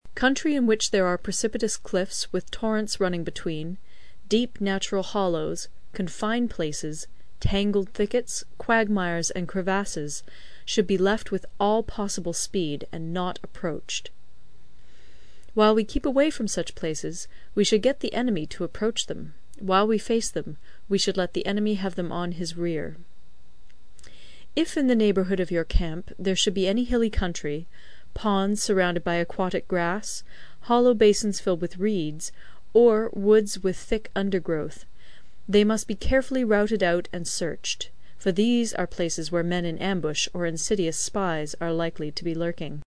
有声读物《孙子兵法》第52期:第九章 行军(3) 听力文件下载—在线英语听力室